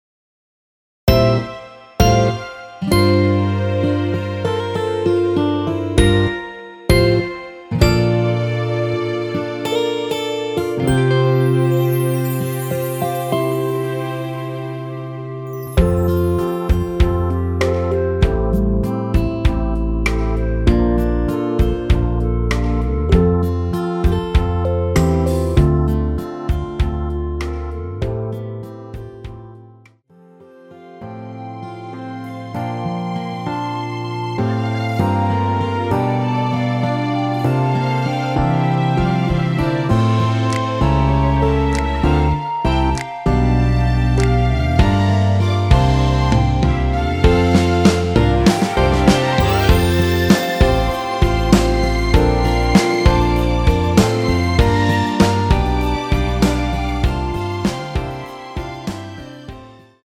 원키에서(+3)올린 멜로디 포함된 MR입니다.(미리듣기 확인)
앞부분30초, 뒷부분30초씩 편집해서 올려 드리고 있습니다.
중간에 음이 끈어지고 다시 나오는 이유는